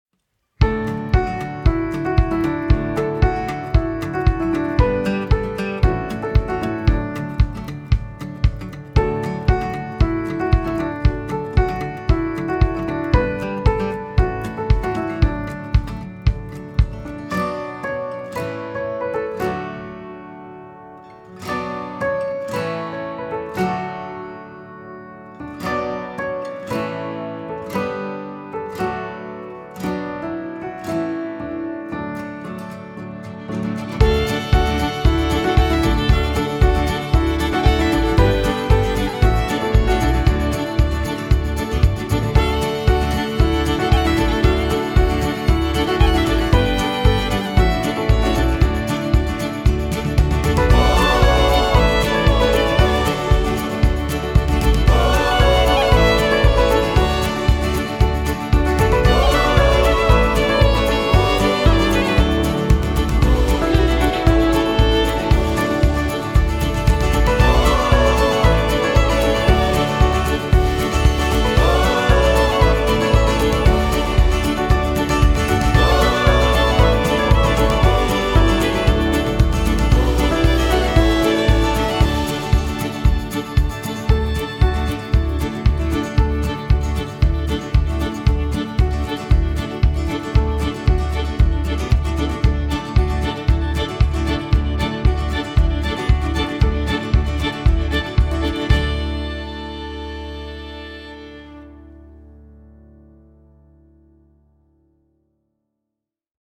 composer // Singersongwriter